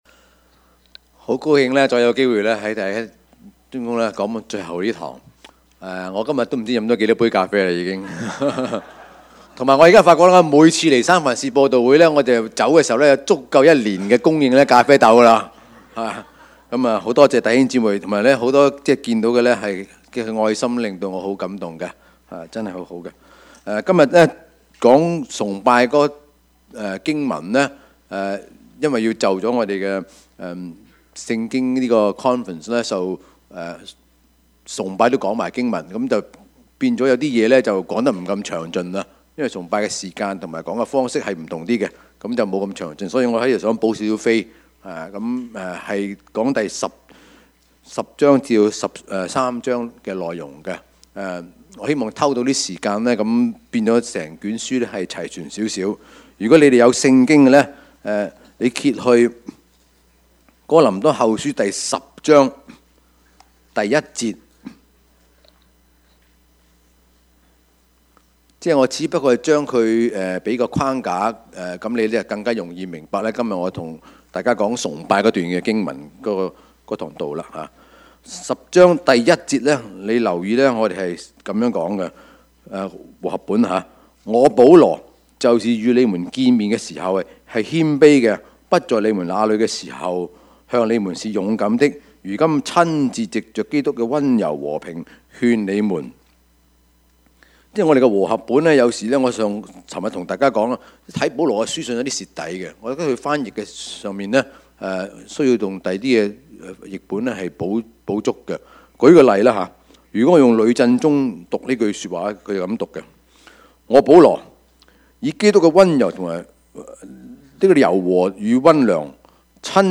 Passage: 哥林多後書 八：6-24 Service Type: 主日崇拜